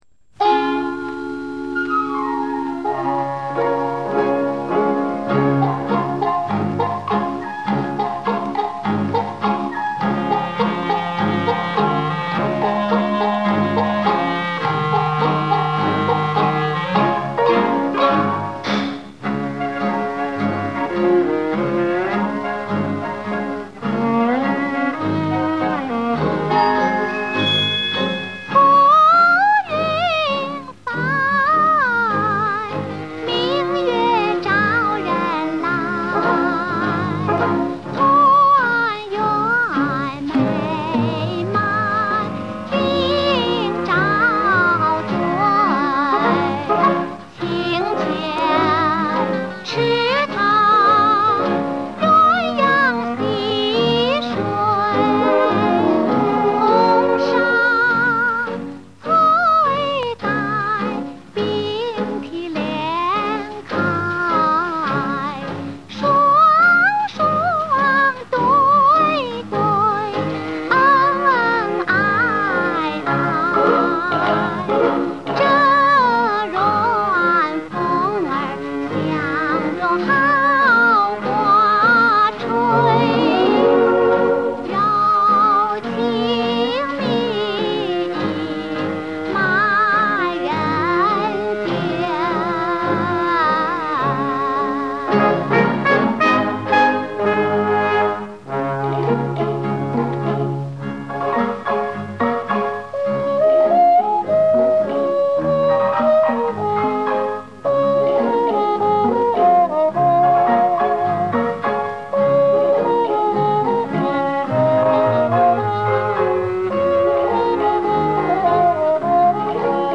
原唱